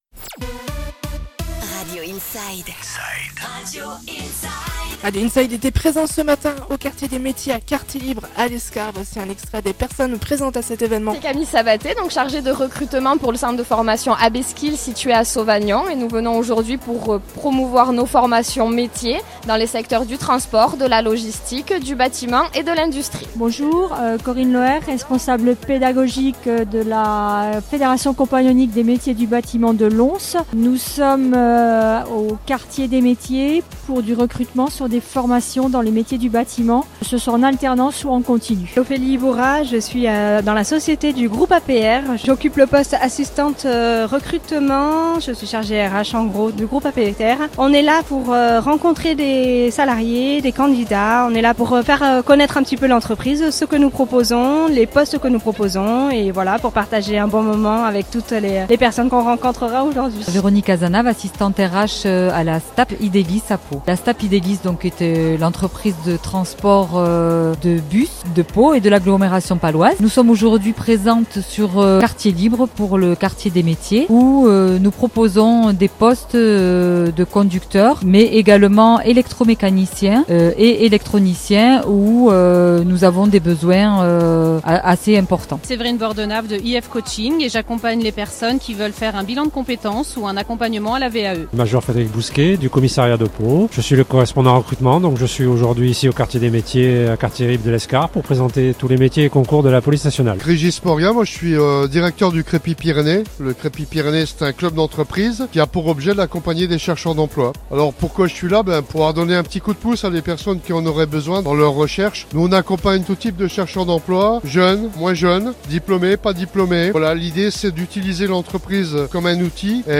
Radio Inside était présente ce matin au Quartier des Métiers à Lescar pour reccueillir plusieurs témoignages d'entreprises à la recherche de nouveaux talents, ainsi que des organismes, des centres de formations offrant un accompagnement professionnnel.